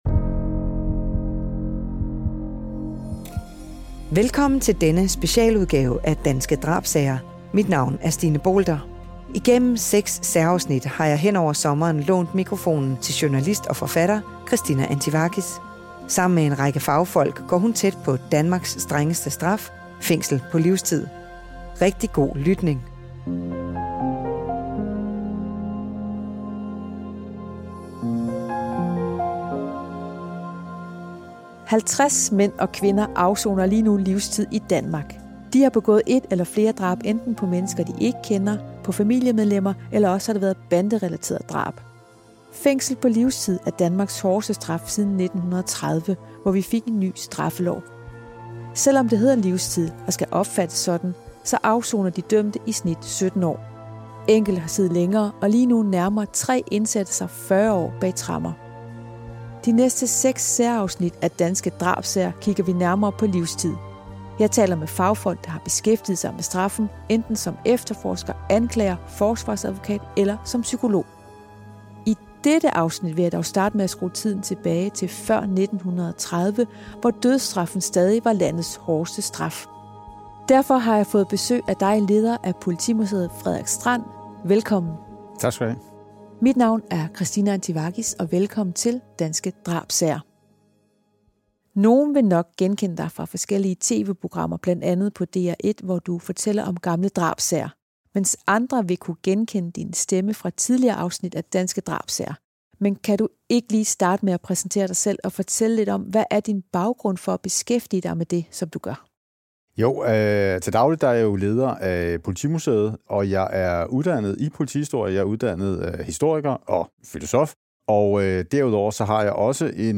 Vi taler bl.a. med fagfolk, der har straffen tæt inde på livet enten som efterforsker, anklager eller forsvarsadvokat. I første afsnit skruer vi tiden tilbage før 1930, hvor livstid erstattede dødsstraffen. Vi hører om Danmarks sidste henrettelse i fredstid af Jens Nielsen i Fængslet i Horsens, og vi taler om, hvorfor vi afskaffede dødsstraffen.